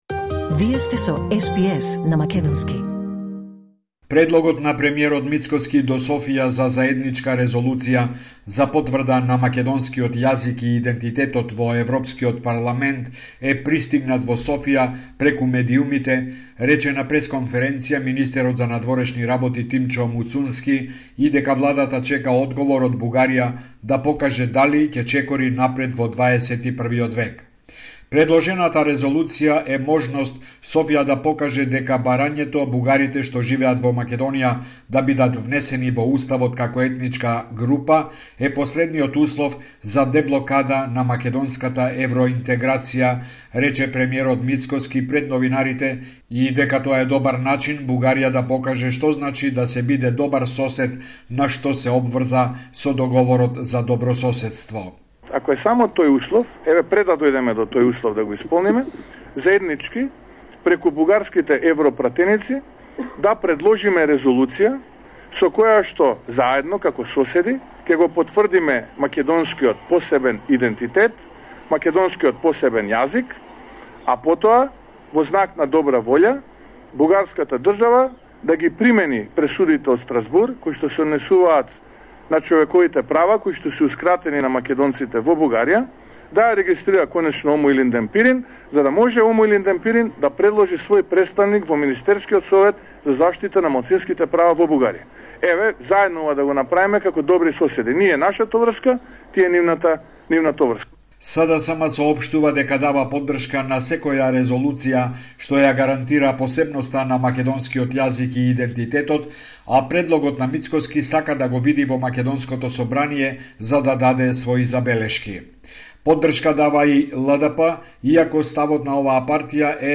Извештај од Македонија 22 јули 2025